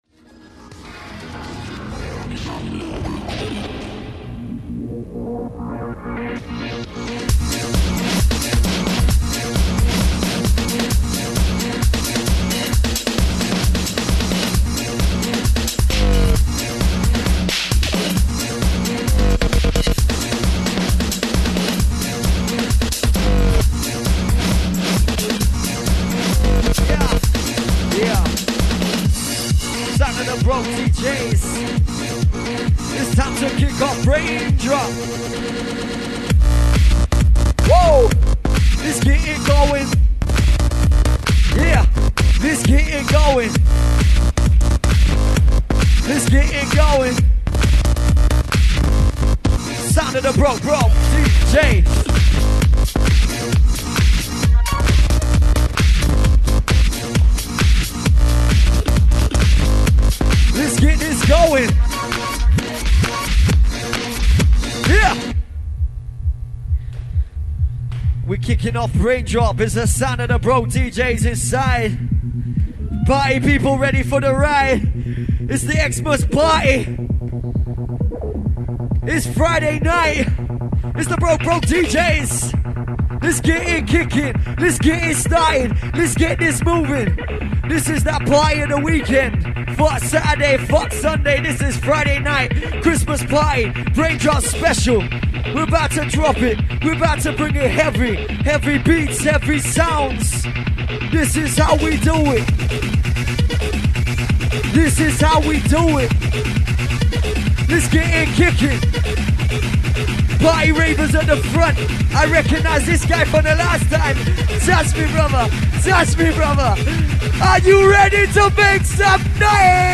first up in the main room
proper heavy set of breakz driven belters